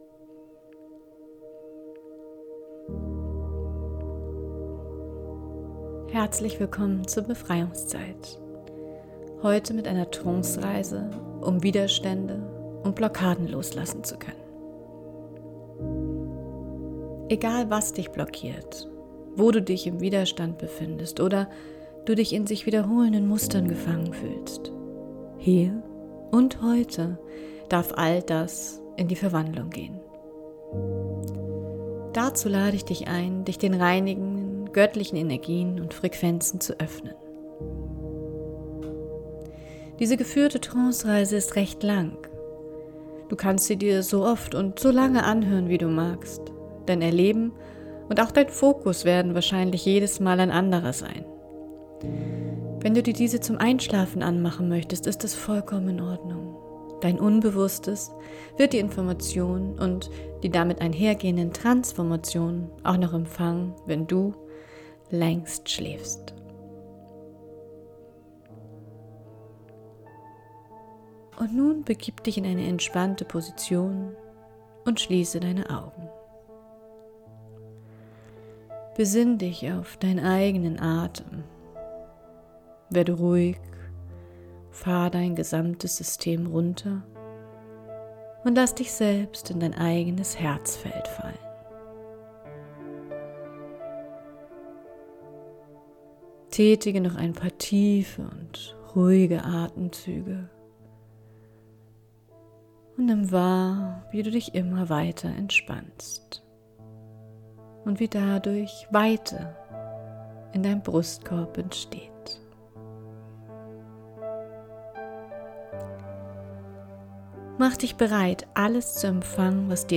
Geführte Trancereise.